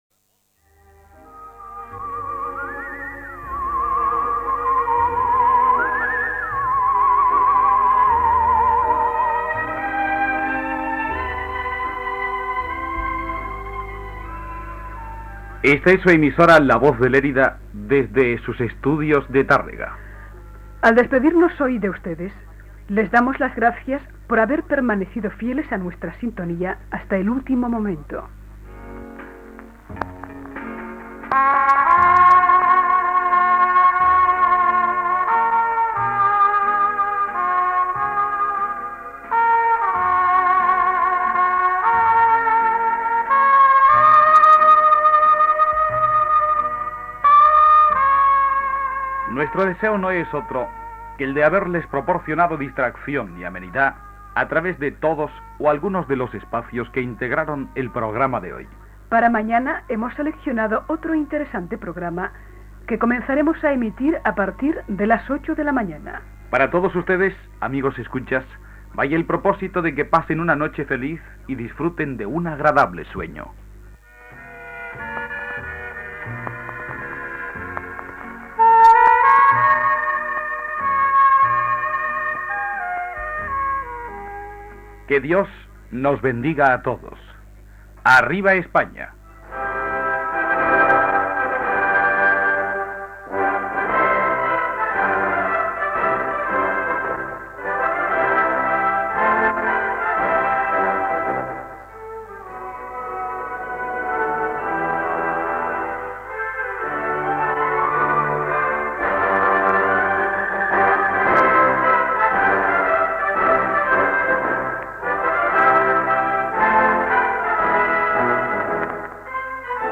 Tancament de l'emissió i Himno de la Organización Sindical Española